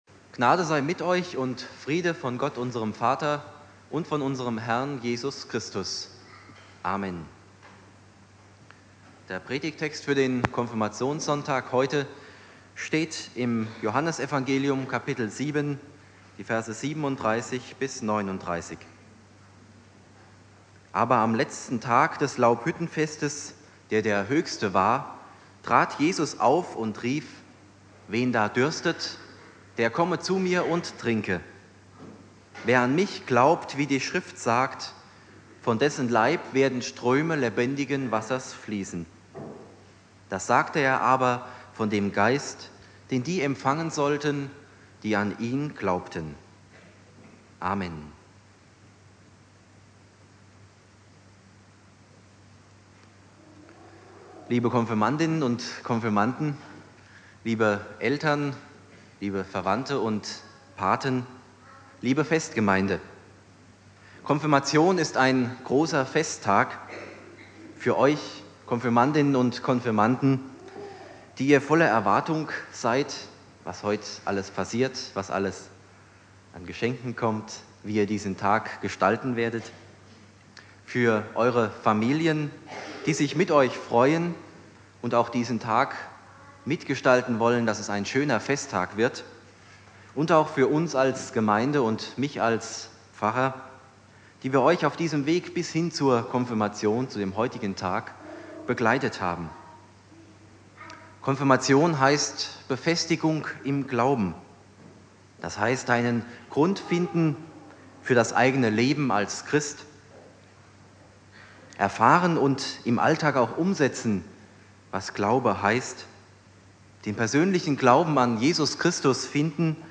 Predigt
Thema: "Hunger und Durst" (Konfirmation Hausen)